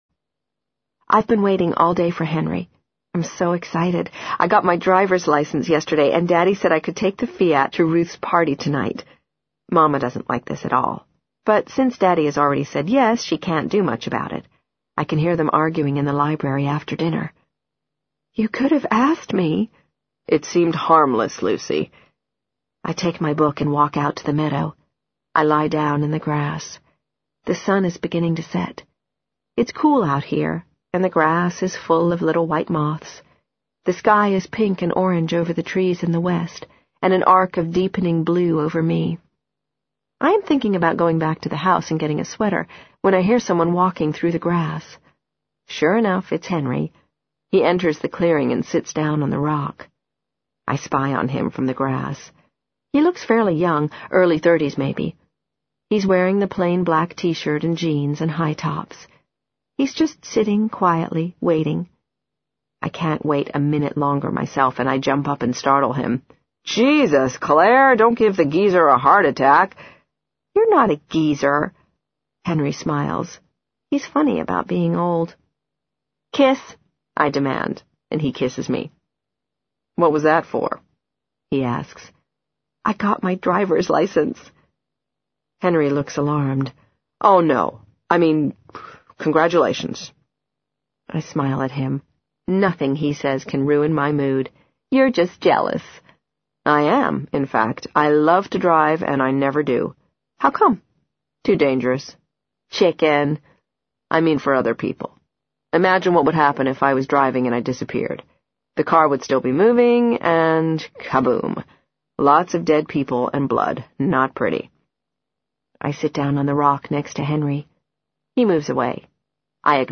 在线英语听力室【时间旅行者的妻子】72的听力文件下载,时间旅行者的妻子—双语有声读物—英语听力—听力教程—在线英语听力室